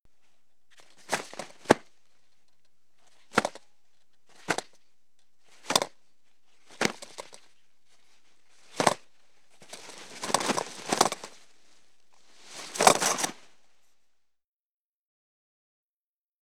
household
Toilet Paper from Roll Hit and Spin